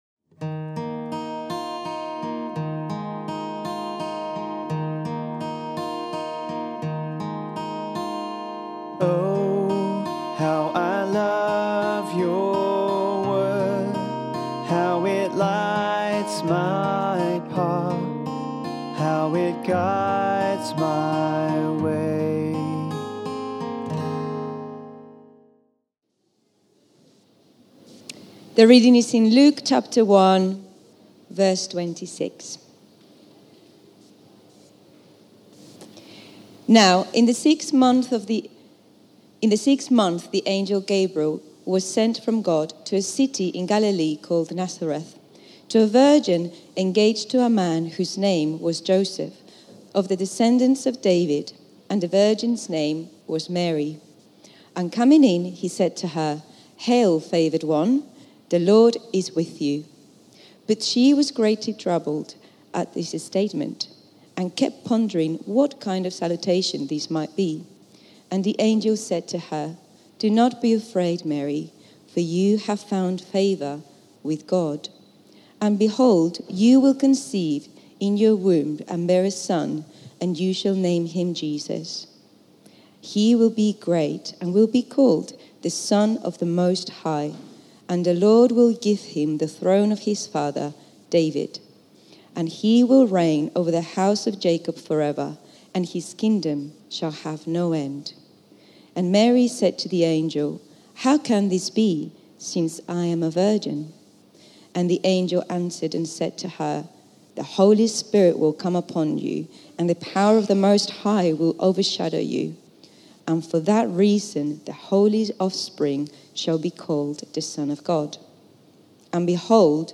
Advent Bible talks